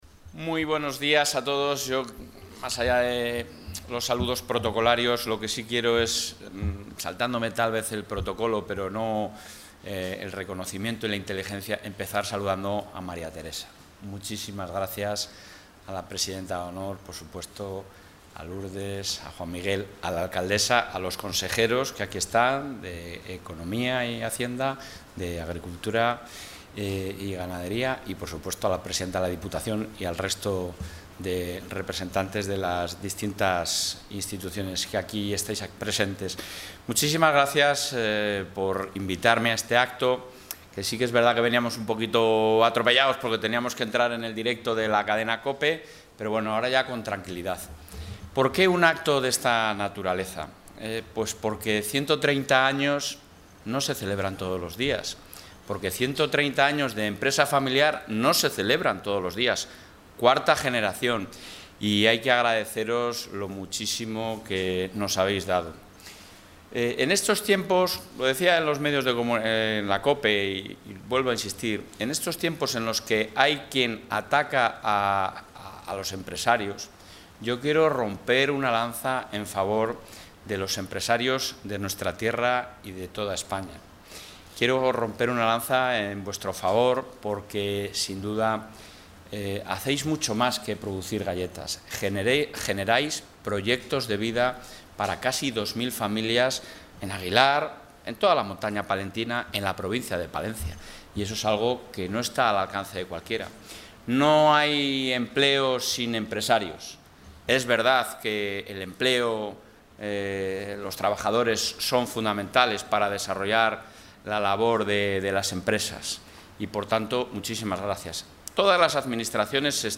El presidente de la Junta de Castilla y León, Alfonso Fernández Mañueco, ha participado hoy, en Aguilar de Campoo (Palencia), en...
Intervención del presidente.